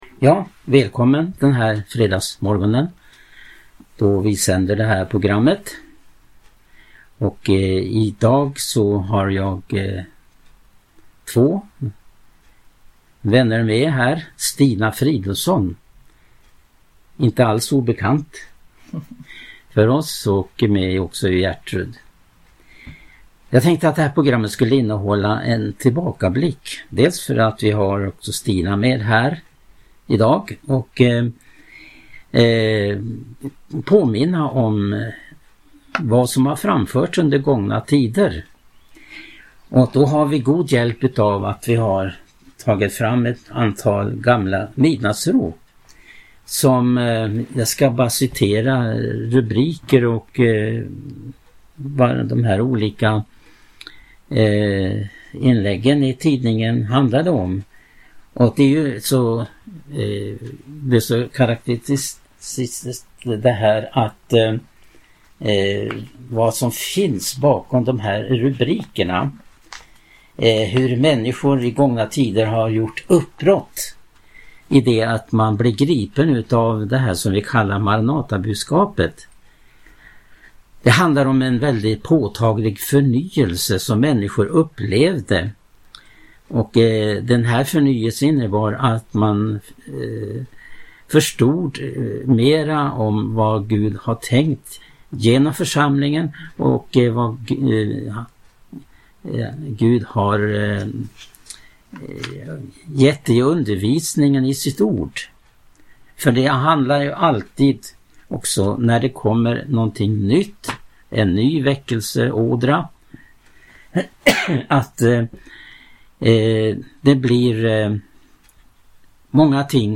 Samtal om Maranatafolkets historia